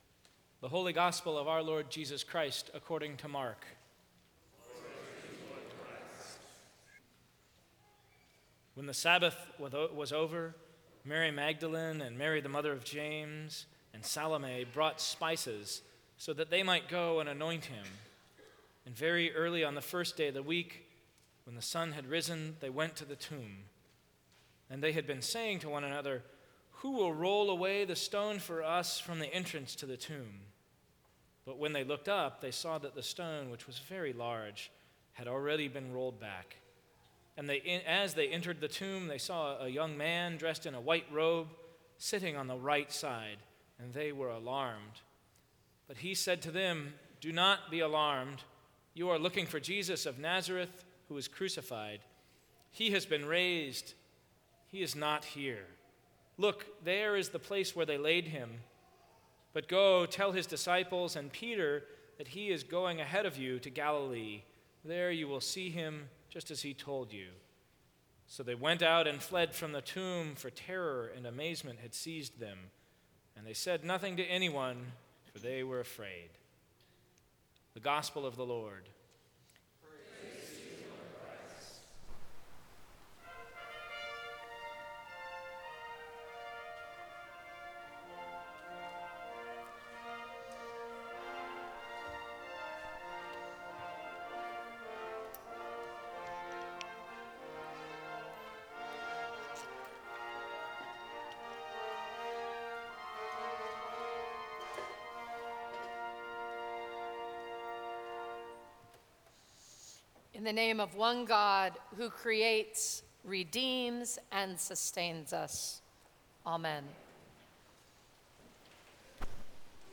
Sermons from St. Cross Episcopal Church Jesus Has Risen!